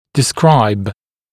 [dɪ’skraɪb][ди’скрайб]описывать; изображать; характеризовать